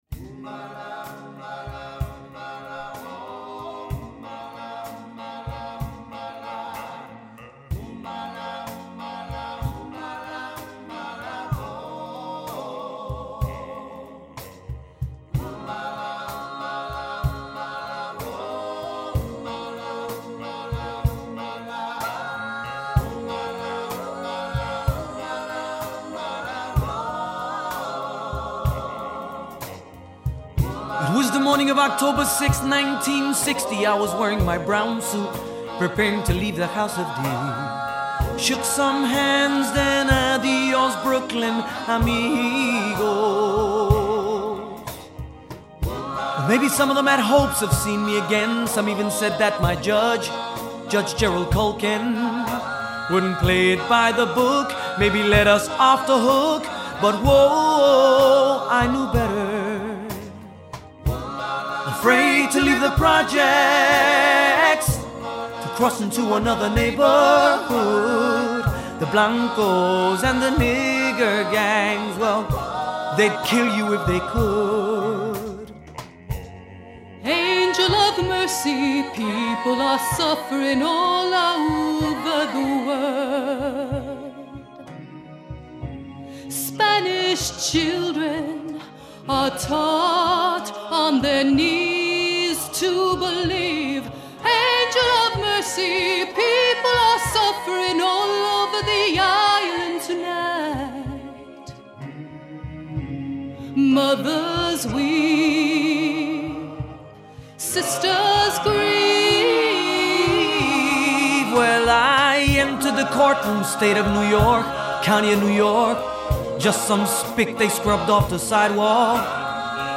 1997   Genre: Musical   Artist